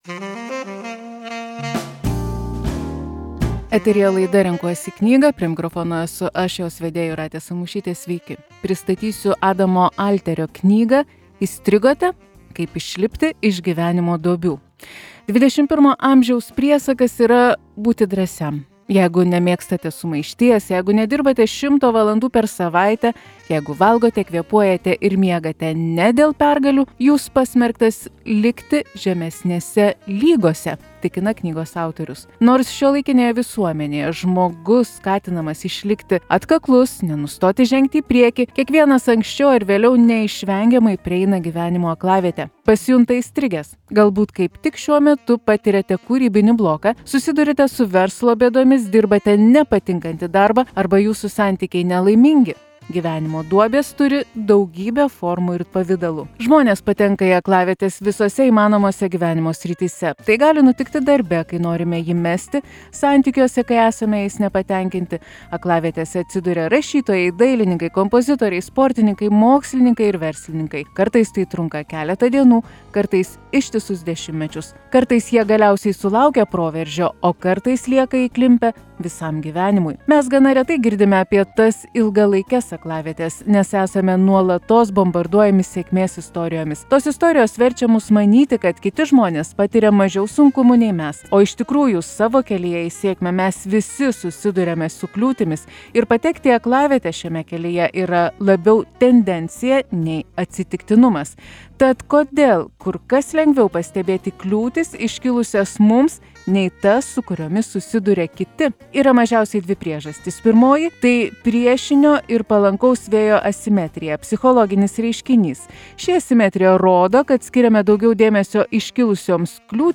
Knygos apžvalga.